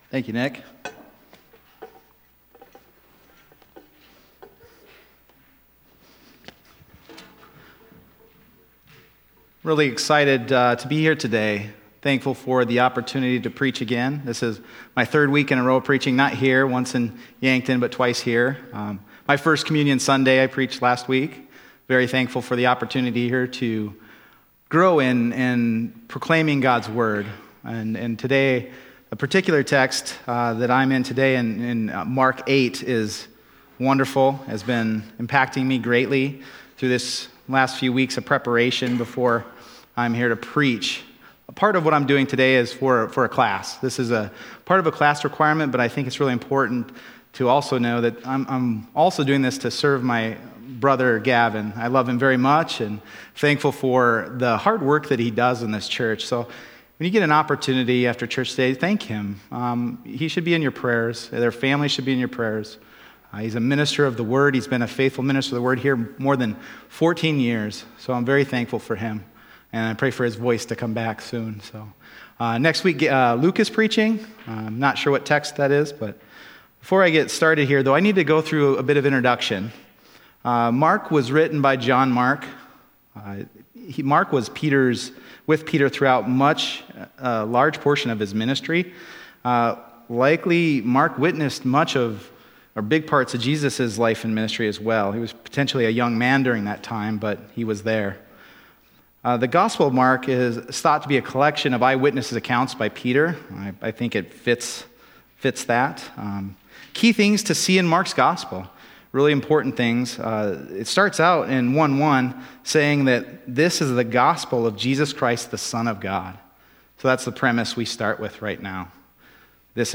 Mark 8:34-38 Service Type: Sunday Morning Mark 8:34-38 « Exalted Christ